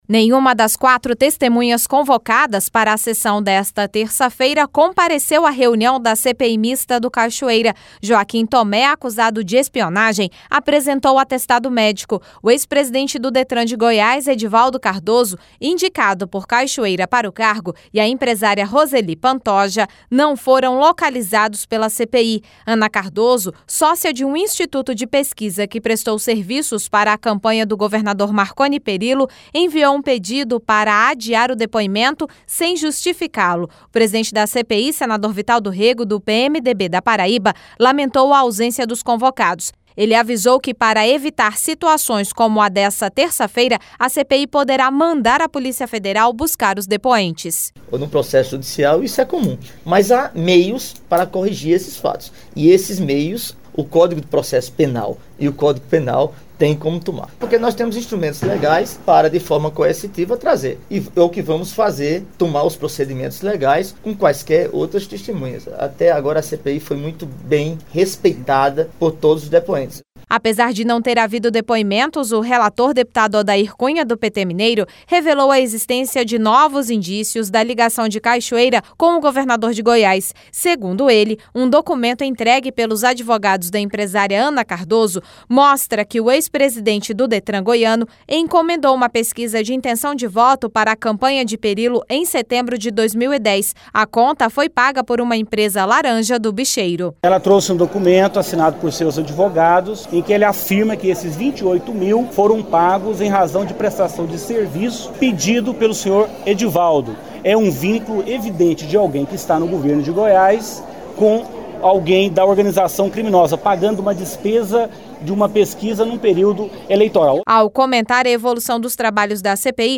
O presidente da CPI, senador Vital do Rêgo, do PMDB da Paraíba, lamentou a ausência dos convocados.